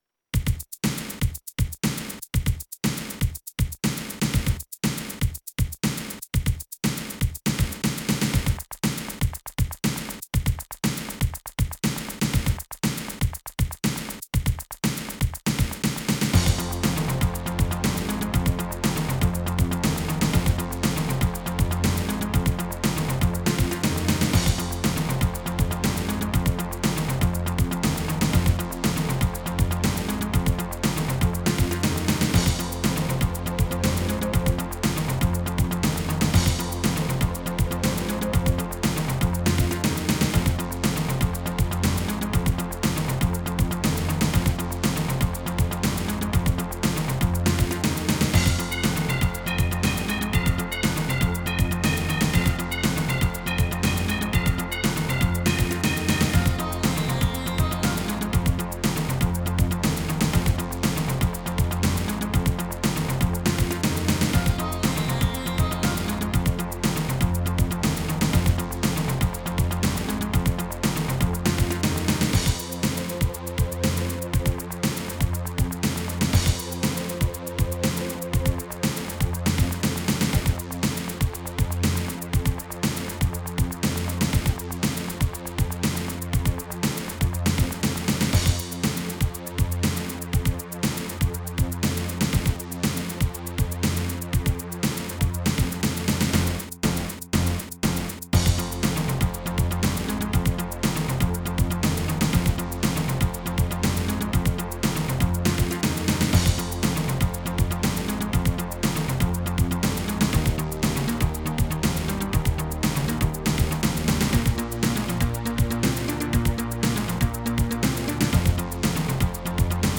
sid commodore 64 c64 remix